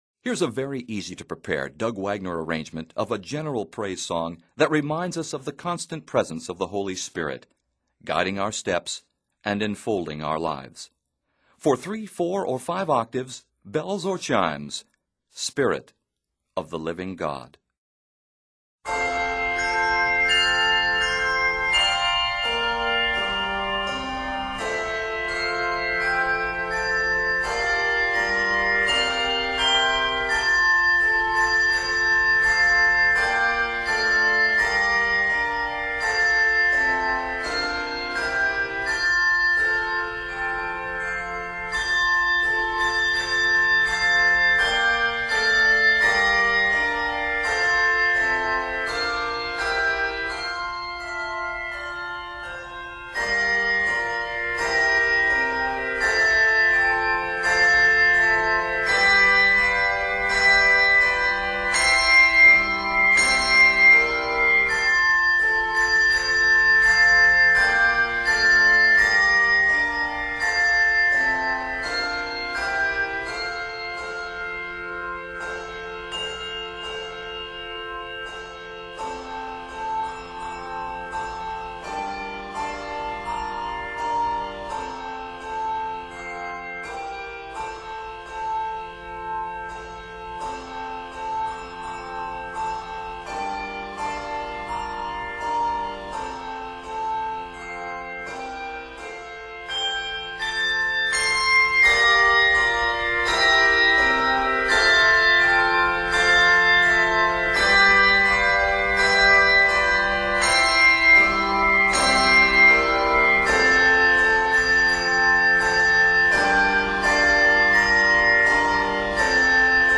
Set in F Major, this piece is 69 measures.